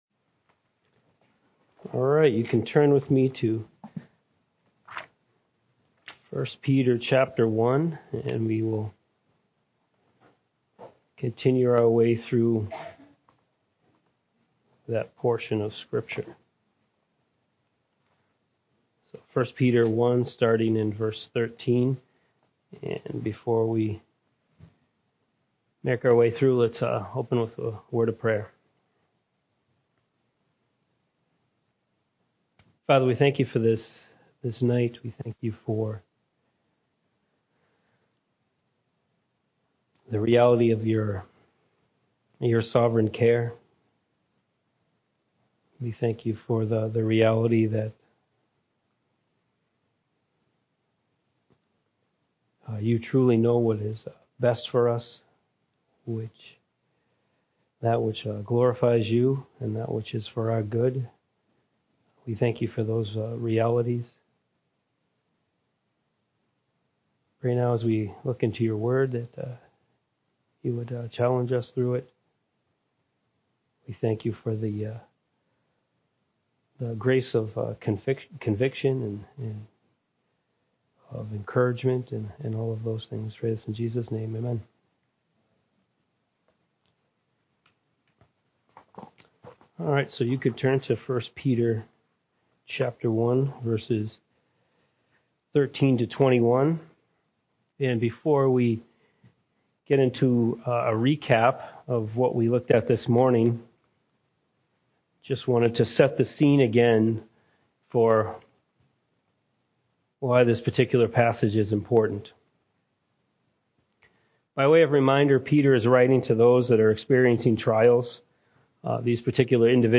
Archived Sermons - Fellowship Bible Church